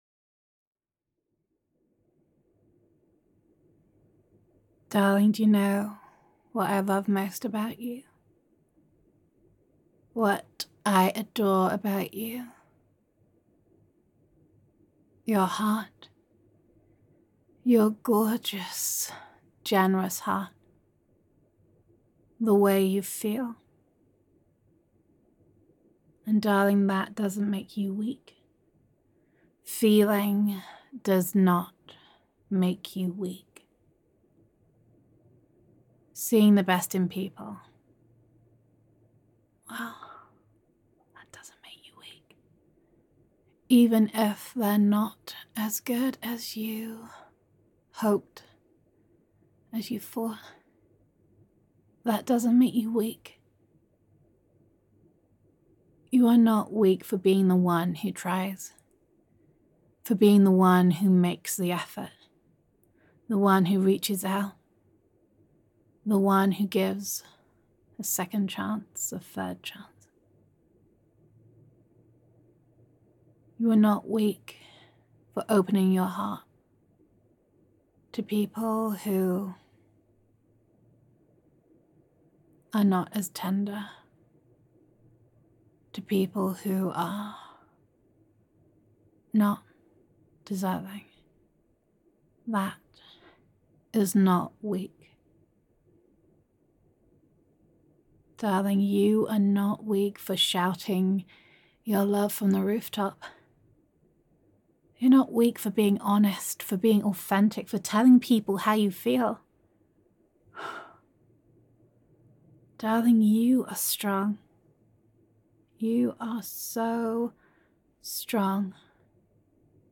[F4A] Your Gorgeous Heart [Strength][Vulnerability][It Is Alright to Feel Deeply][Girlfriend Roleplay][Gender Neutral][Reassurance That It Is Okay to Feel]